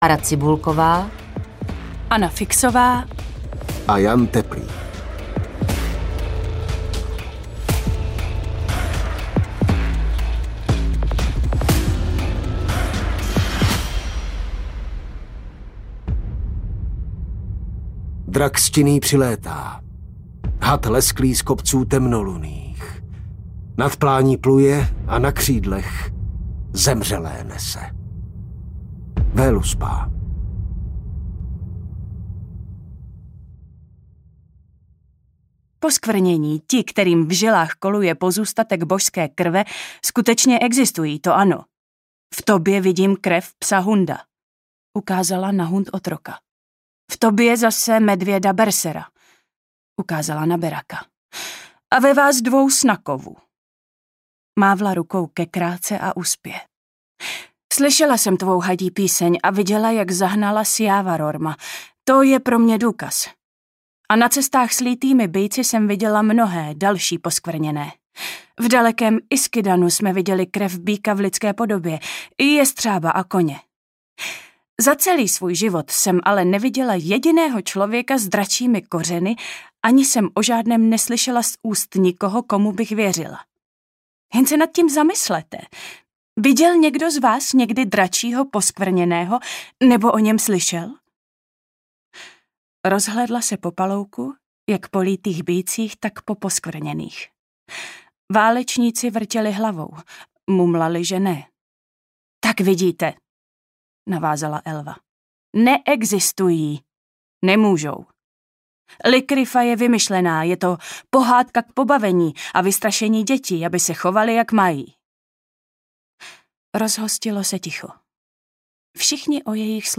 Stín bohů audiokniha
Ukázka z knihy